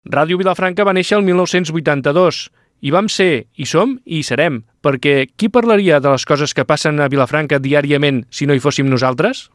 Enregistrament fet amb motiu del Dia Mundial de la Ràdio 2022.
FM